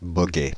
Ääntäminen
Synonyymit cyclomoteur mobylette vélomoteur Ääntäminen France (Île-de-France): IPA: /bɔ.ɡɛ/ Paris: IPA: [bɔ.ɡɛ] Haettu sana löytyi näillä lähdekielillä: ranska Käännöksiä ei löytynyt valitulle kohdekielelle.